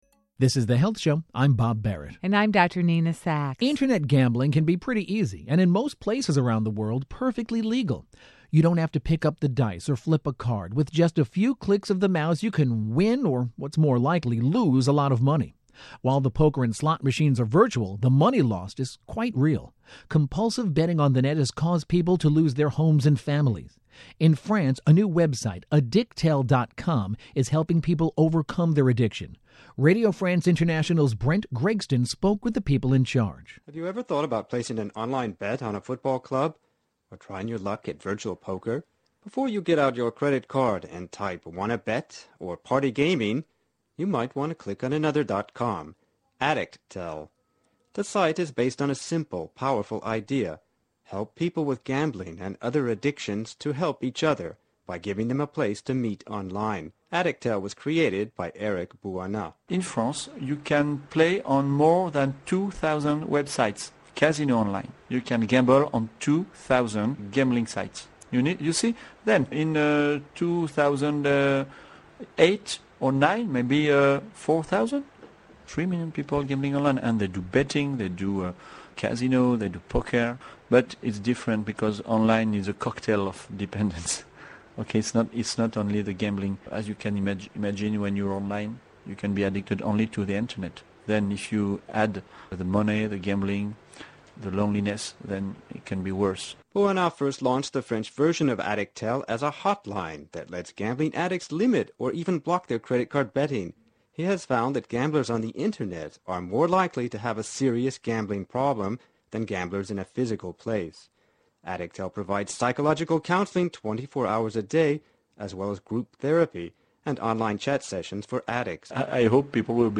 EUROPE 1 - INTERVIEW